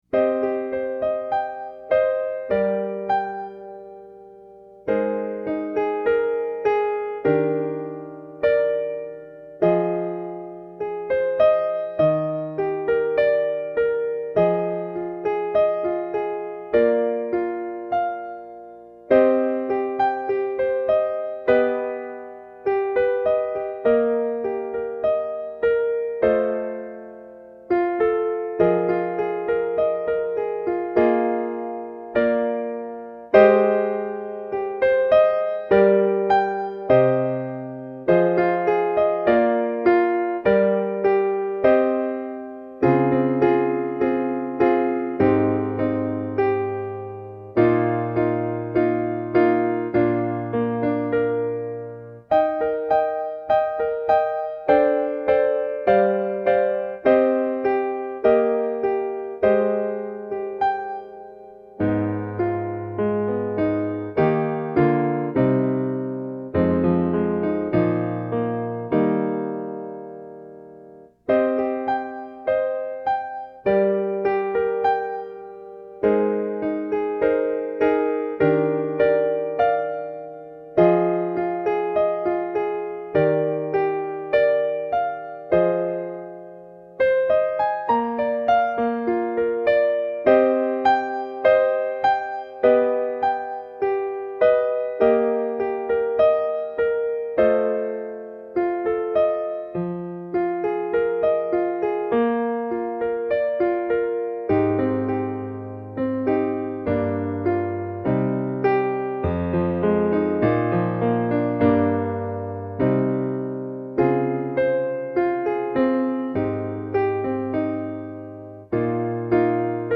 SEE ME IN EVERY SMILE – piano in progress
every-smile-midi-piano-1-18-15.mp3